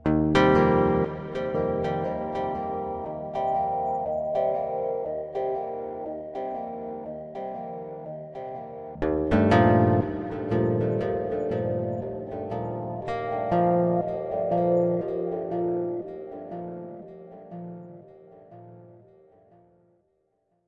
延迟吉他" 22 O1 107 1
描述：一包基于延迟的吉他声音，具有极简主义的悠闲感觉。
Tag: 气氛 气氛 电子 吉他 音乐 处理 节奏